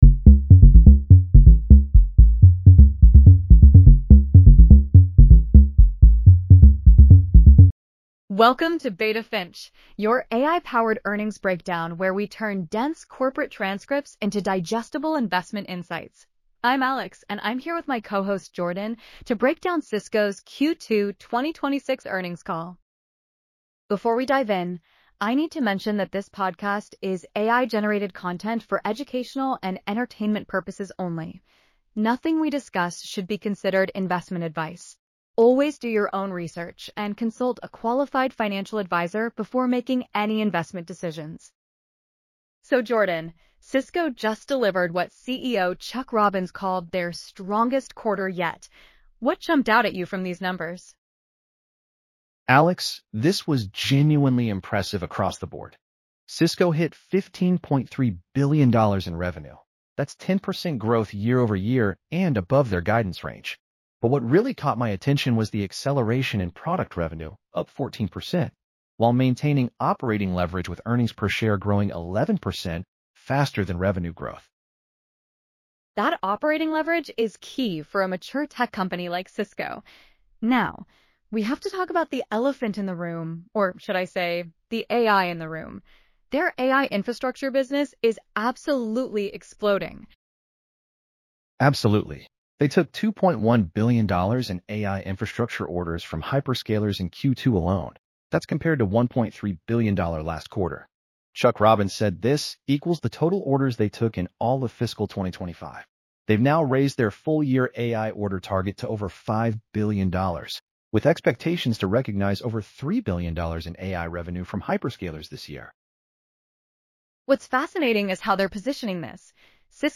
Beta Finch Podcast Script - Cisco Q2 2026 Earnings